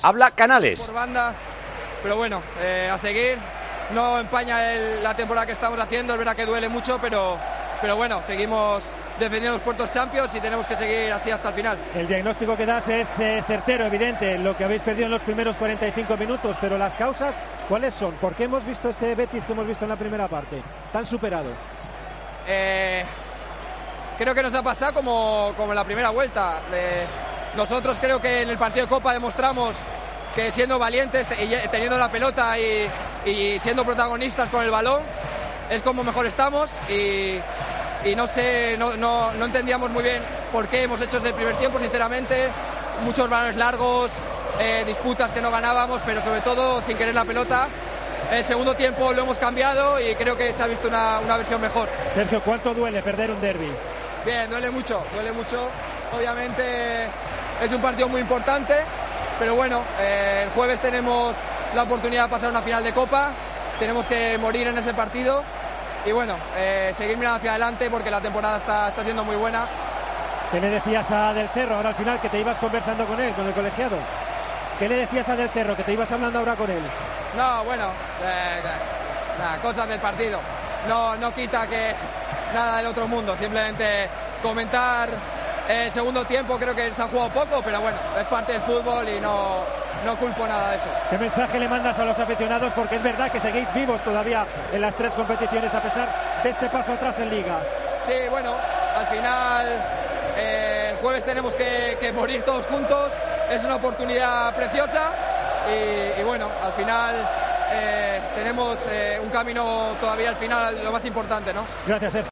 El jugador del Betis valoró la derrota de su equipo ante el Sevilla en declaraciones a Movistar+: "No empaña la temporada que estamos haciendo, aunque duele mucho".